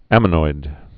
(ămə-noid)